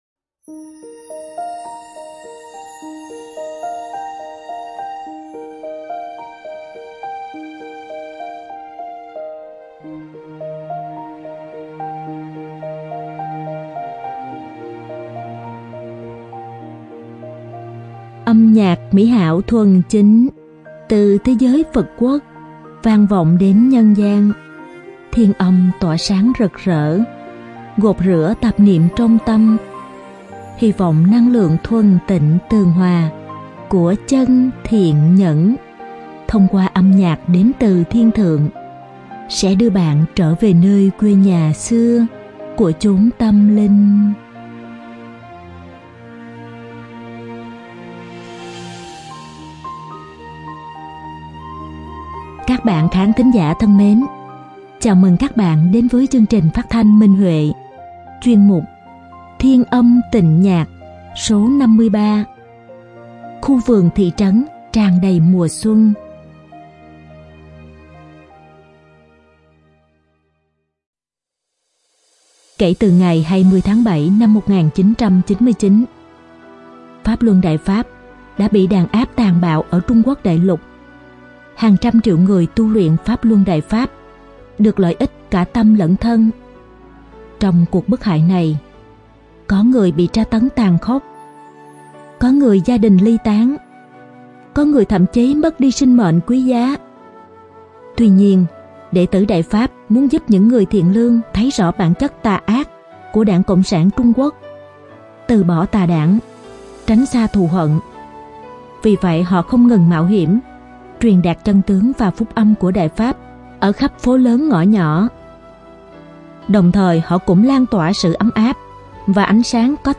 Các bạn khán thính giả thân mến, chào mừng các bạn đến với chương trình phát thanh Minh Huệ, chuyên mục “Thiên Âm Tịnh Nhạc” Số 53: Khu vườn thị trấn tràn đầy mùa xuân.